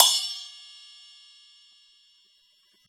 PERC - RESPONSE.wav